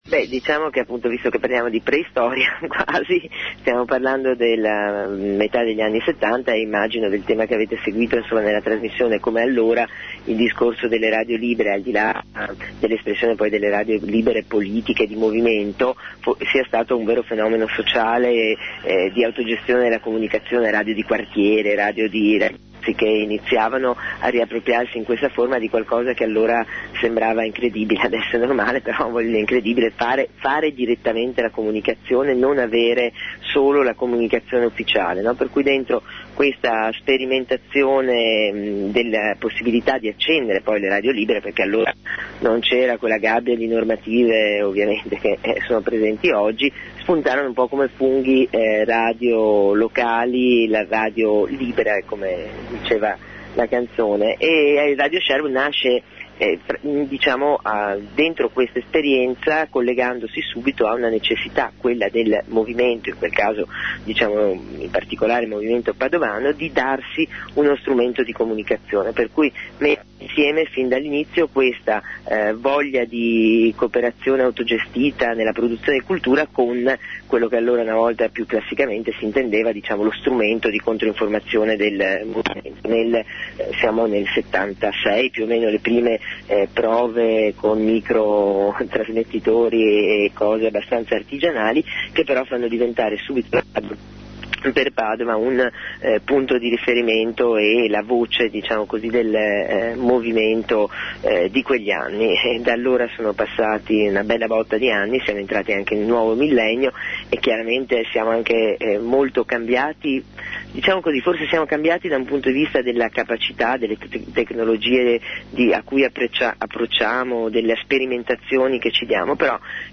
Intervista a Radio Sherwood